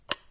switch29.wav